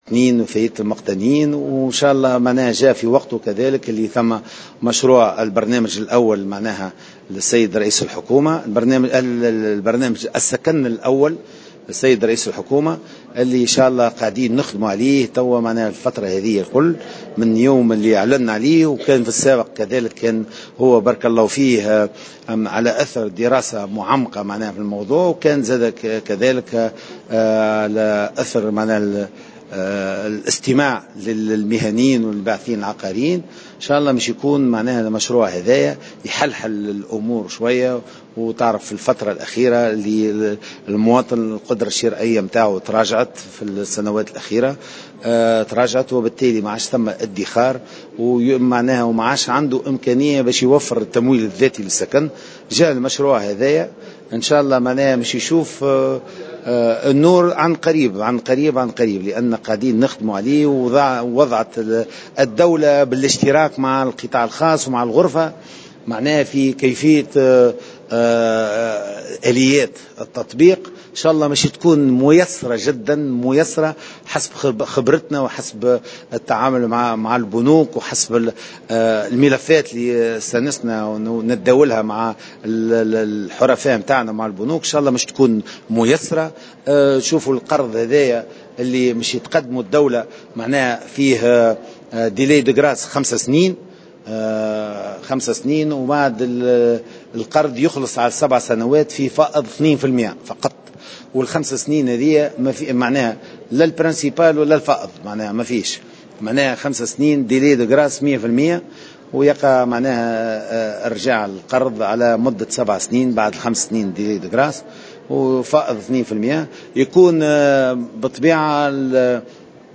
كما يشترط أن لا يتجاوز أحد الزوجين الـ 40 عاما وان يكونا متزوجين منذ أكثر من 10 سنوات. وأضاف في تصريح لمراسل "الجوهرة أف أم" أن هذا المشروع يتمتع بشروط ميسرة على مستوى القرض الذي ستقدمه الدولة حيث تم اقرار 5 سنوات امهال قبل تسديد القرض على امتداد 7 سنوات وبفائض 2 بالمائة.